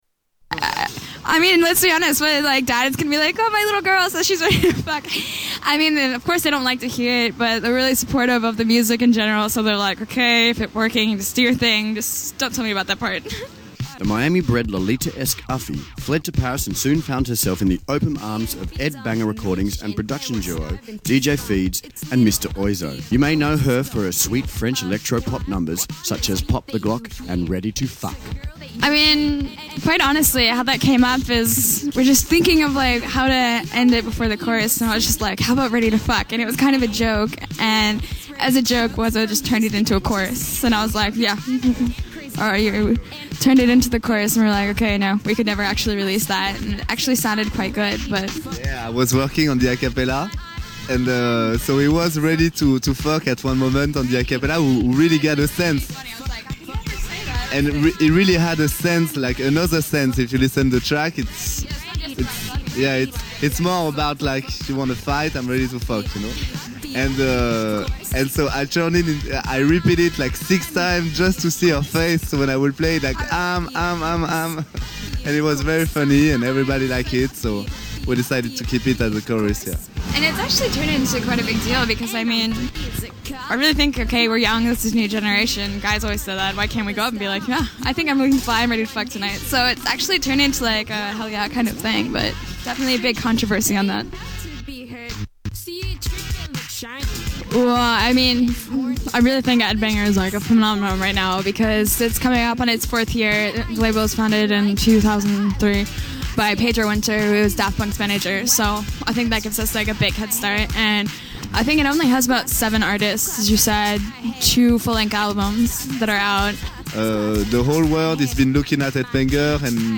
Uffie Interview